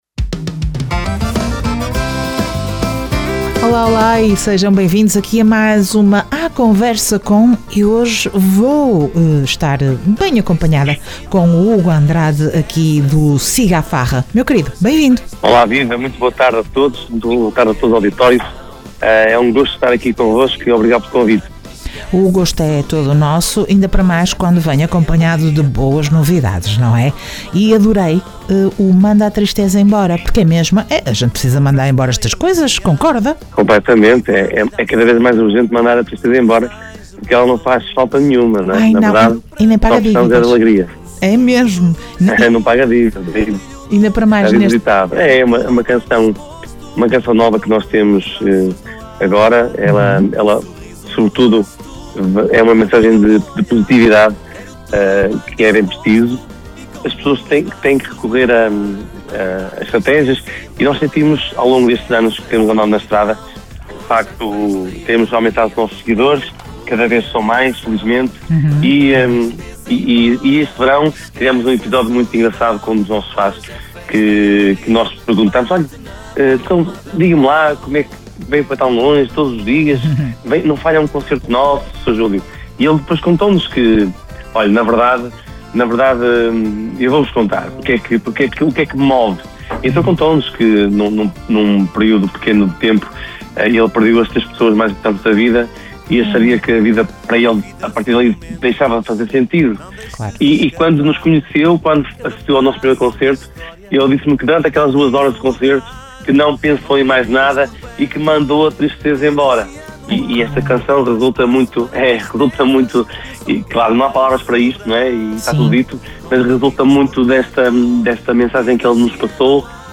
Entrevista Siga a Farra dia 28 de Novembro.